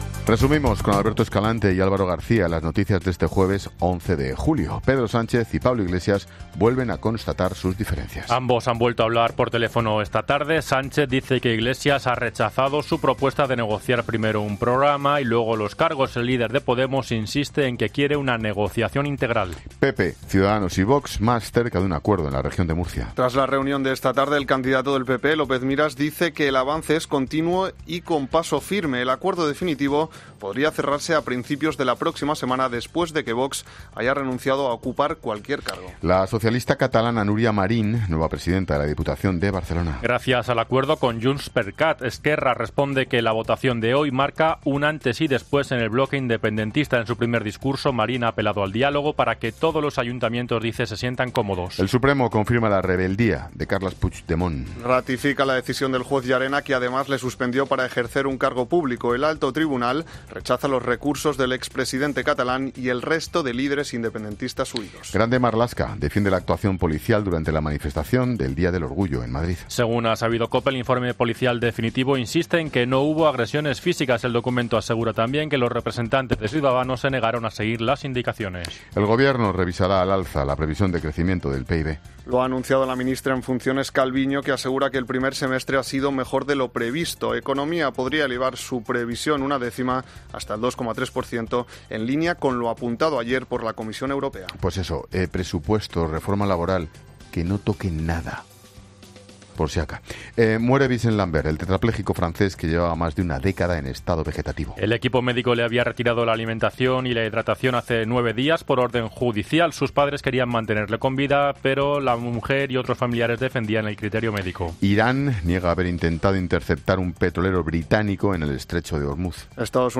Boletín de noticias de COPE del 11 de julio de 2019 a las 20:00 horas